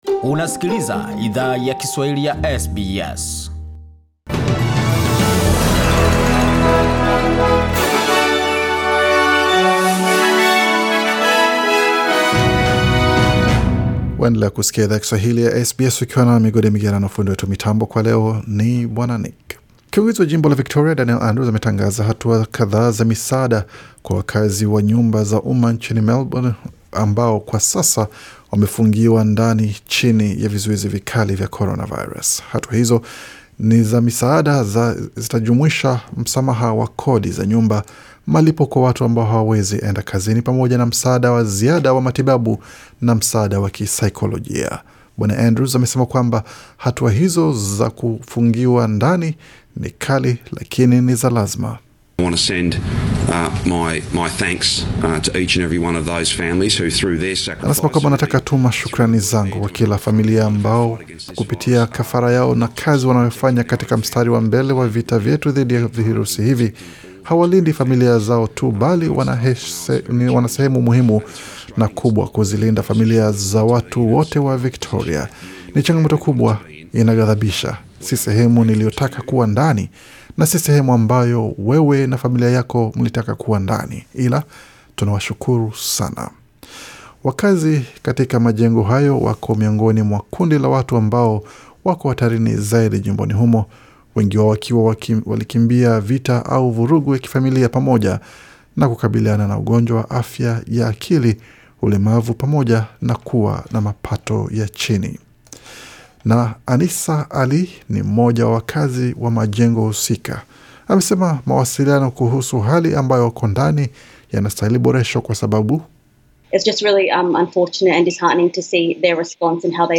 Taarifa ya habari 5 Julai 2020